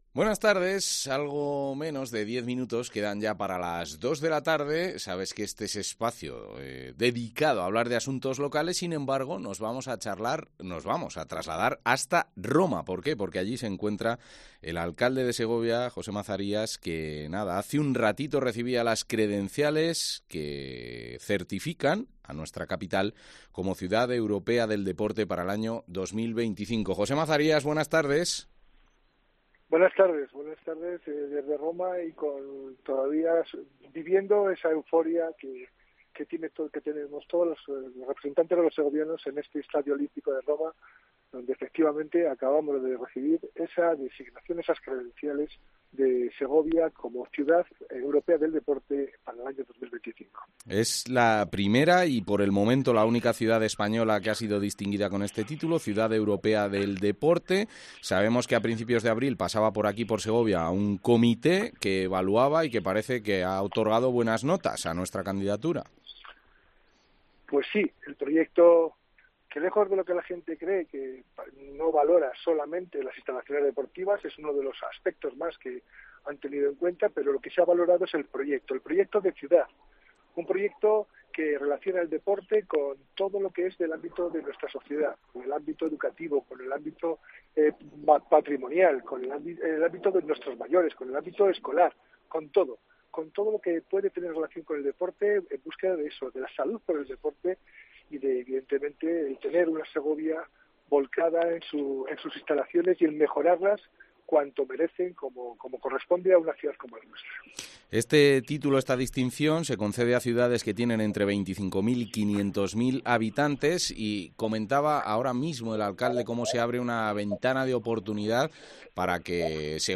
Desde Roma, el alcalde de la capital segoviana, José Mazarías, valora la designación